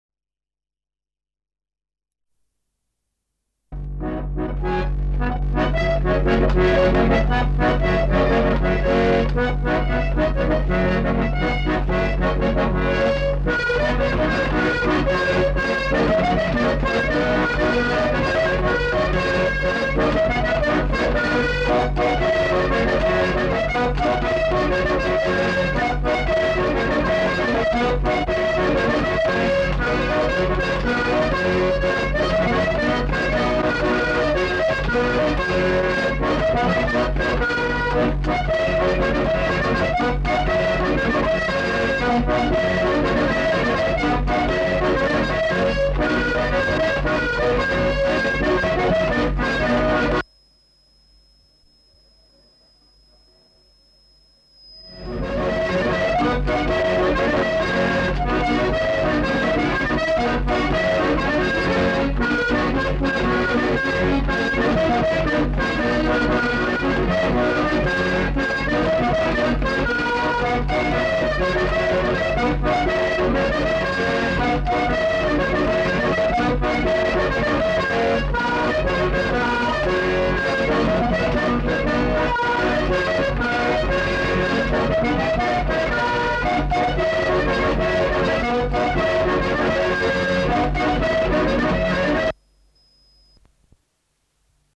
Lieu : Polastron
Genre : morceau instrumental
Instrument de musique : accordéon diatonique
Danse : borregada